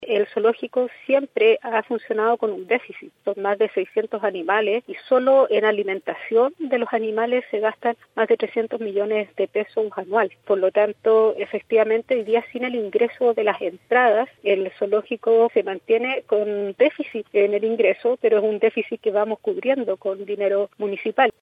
Así indicó en conversación con La Radio la alcaldesa de la comuna, Valeria Melipillán.